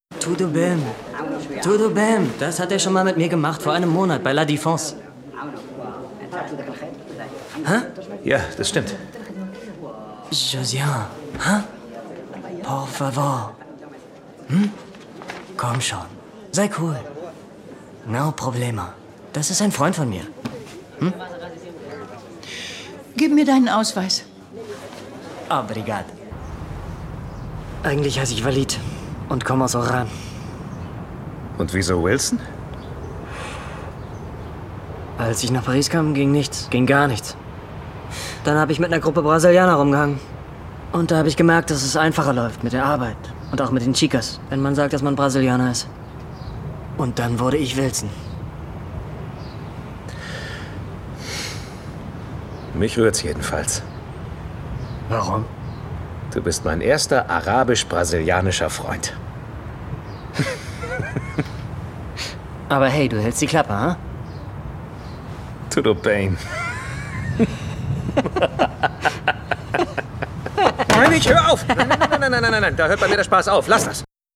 ★ Rolle: Walid (2. Hauptrolle)